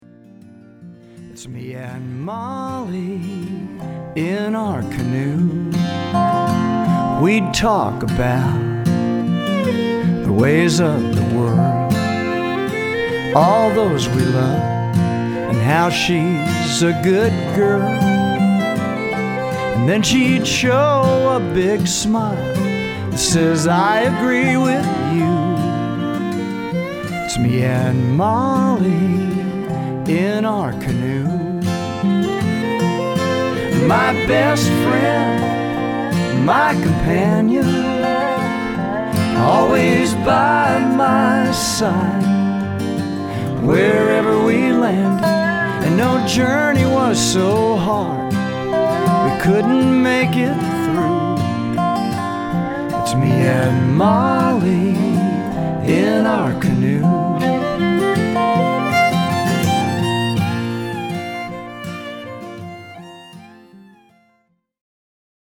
Americana with a touch of country rock